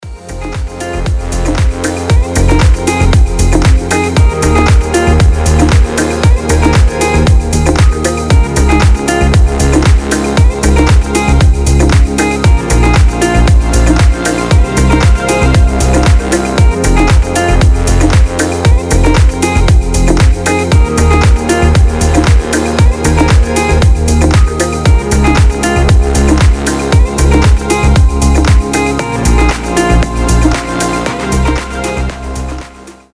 • Качество: 320, Stereo
красивые
deep house
без слов